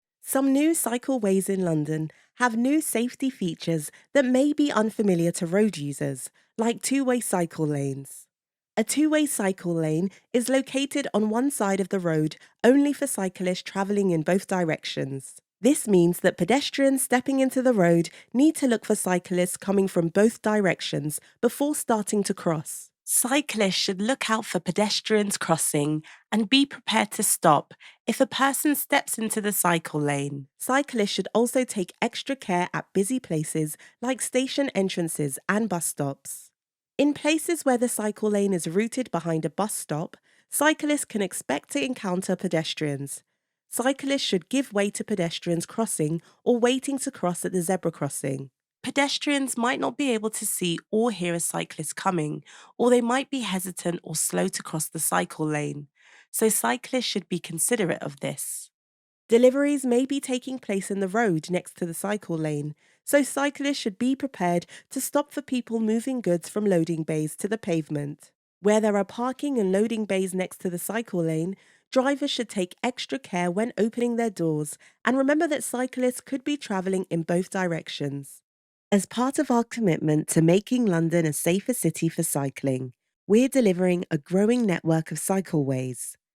Female
E-Learning
All our voice actors have professional broadcast quality recording studios.